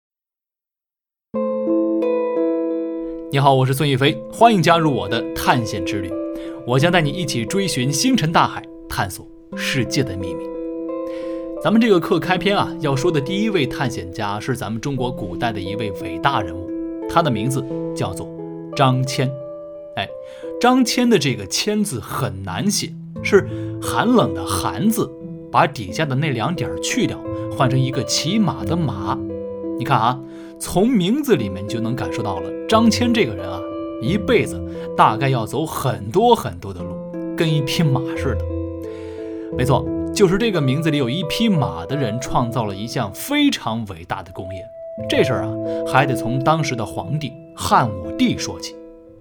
【纪实转述】张骞